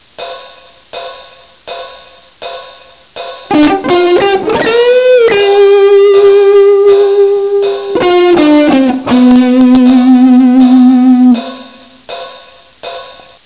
Проверьте Пример 1, вступление, которое я часто играю при медленном блюзе. Заметьте, чтобы создать вибрато, я использую как указательный, так и средний пальцы. Вибрато обеспечивает мягкий контраст агрессивному вступлению.